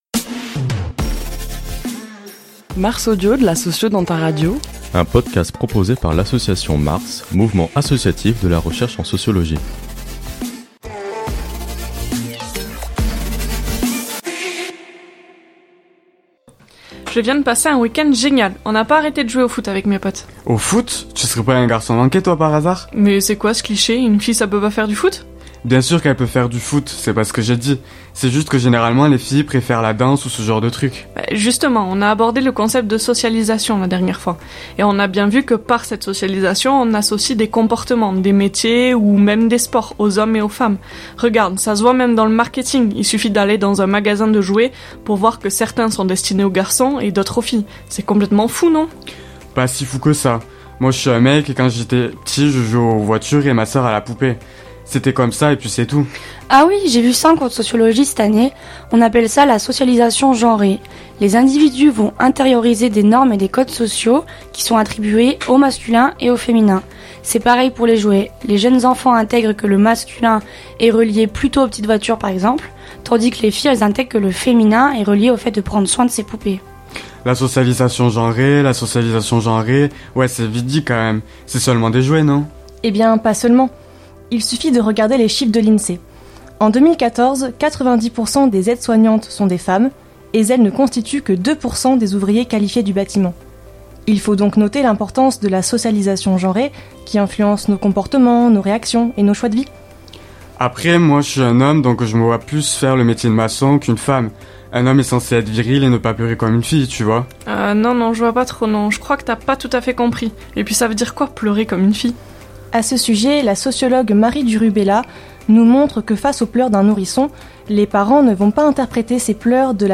Ce mois-ci, MARS Audio vous invite à découvrir le genre à travers un court dialogue fictif. Chaque mois, nous vous présentons un concept clé en sociologie afin de vulgariser cette discipline.